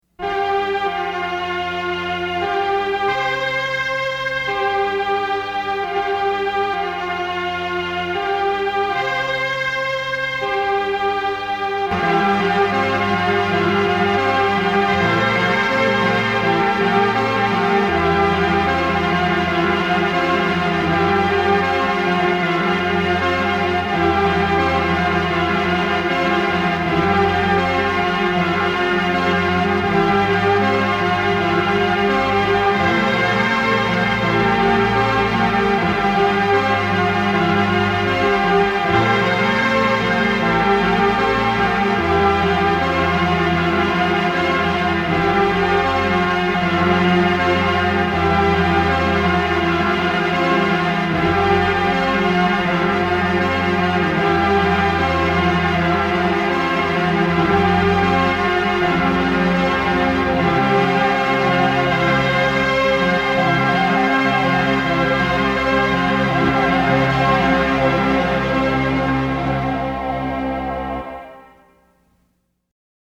Time Counting Out recorded in the year 2010 in Beverly, MA.